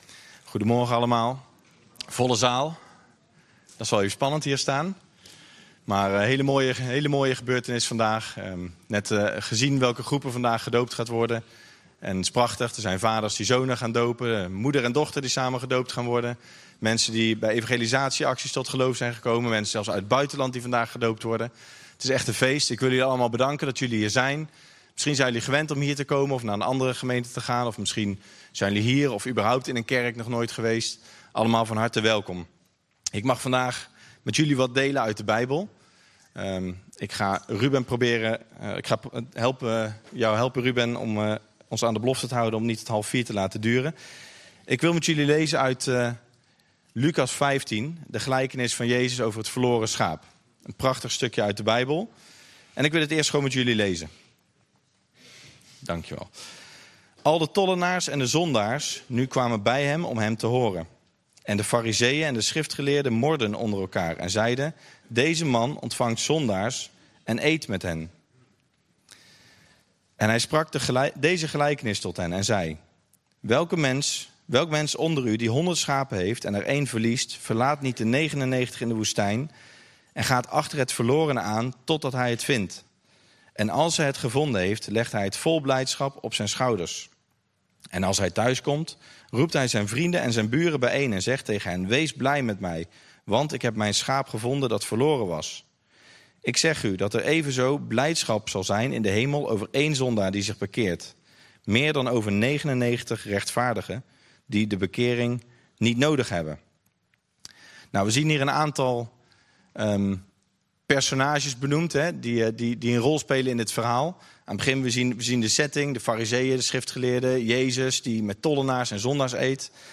Toespraak 30 maart: doopdienst - De Bron Eindhoven